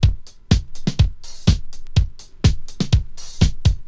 • 124 Bpm Drum Loop F Key.wav
Free drum beat - kick tuned to the F note. Loudest frequency: 902Hz
124-bpm-drum-loop-f-key-bn5.wav